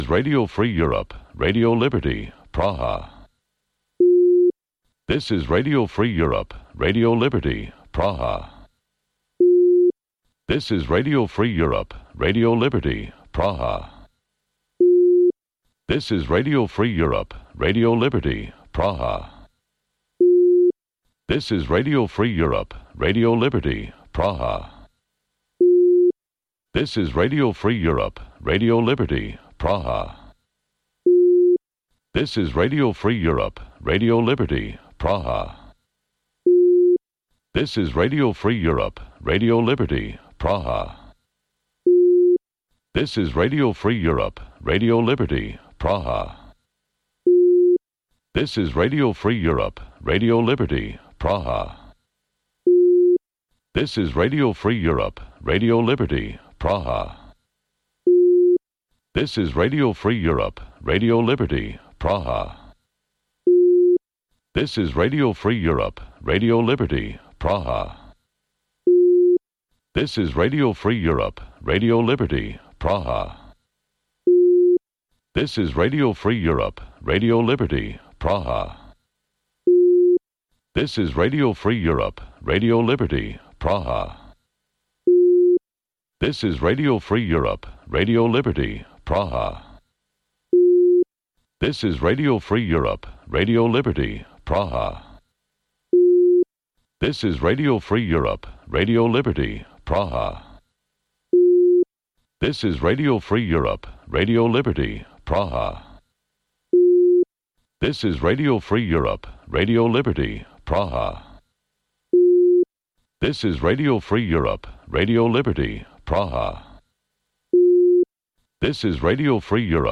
Утринска програма на Радио Слободна Европа од Студиото во Скопје. Во 15 минутната програма од понеделник до петок можете да слушате вести и прилози од земјата, регионот и светот. Во голем дел емисијата е посветена на локални настани, случувања и приказни од секојдневниот живот на граѓаните во Македонија.